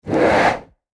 CloseHandheldTorch PE361704
Close Handheld Torch Whoosh, X6